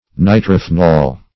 Search Result for " nitrophnol" : The Collaborative International Dictionary of English v.0.48: Nitrophnol \Ni`tro*ph"nol\, n. [Nitro- + phenol.]